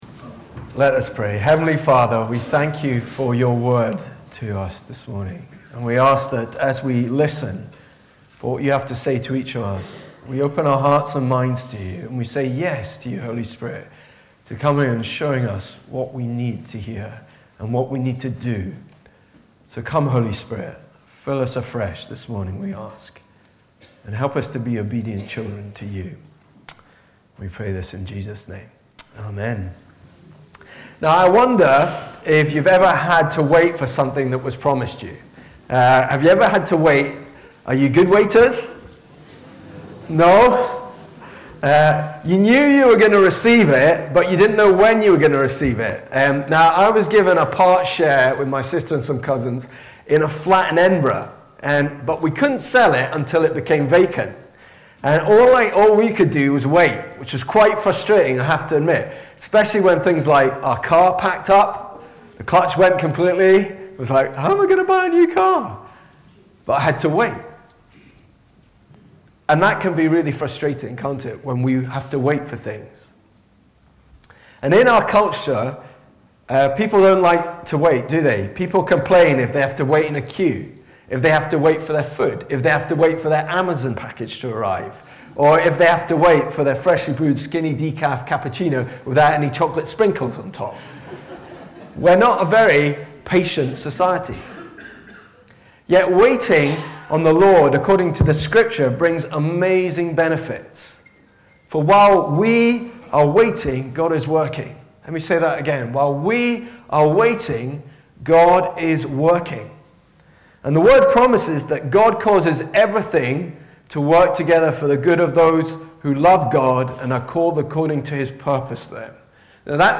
Bible references: Acts 2 Location: Brightons Parish Church Show sermon text NOT AVAILABLE Continuing ministry of Jesus: our role Devoted to teaching, fellowship, communion and prayer The right heart Your Jesus box Waiting